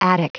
Prononciation du mot attic en anglais (fichier audio)